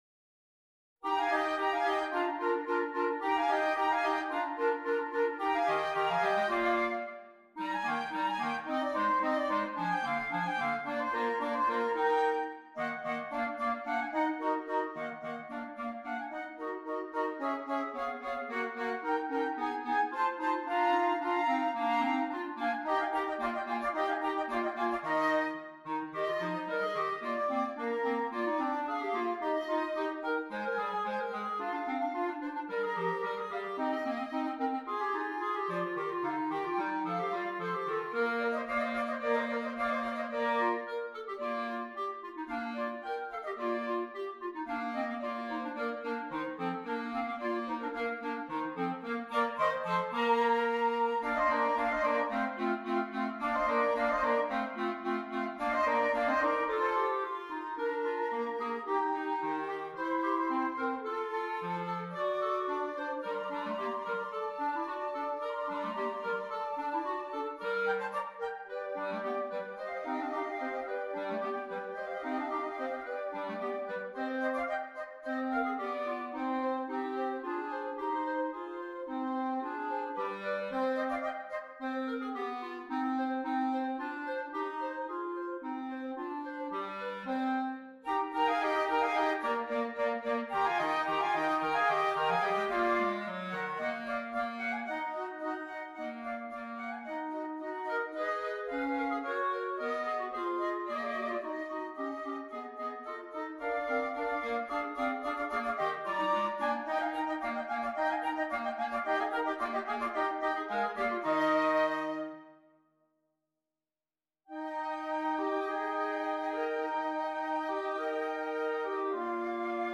2 Flutes, 2 Clarinets
This has been arranged for 2 flutes and 2 clarinets.